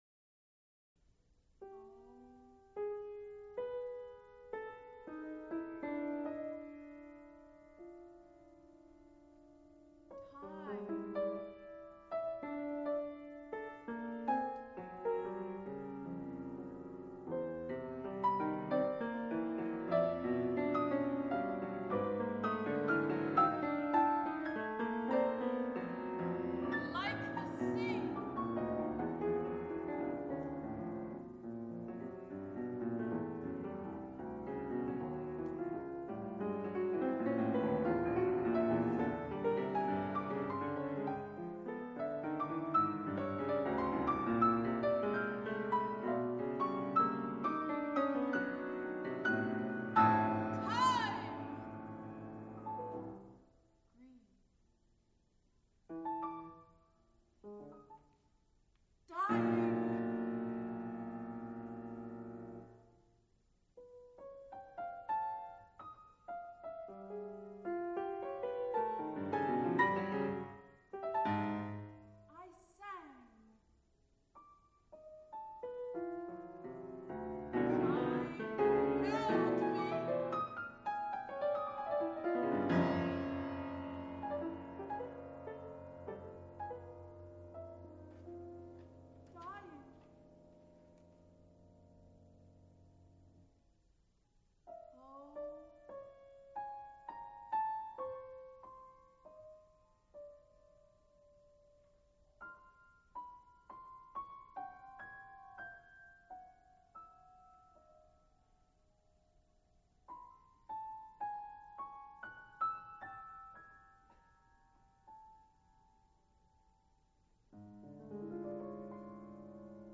piano
(first performance)
Extent 4 audiotape reels : analog, half track, stereo, 7 1/2 ips ; 7 in.
String quartets Monologues with music (Piano)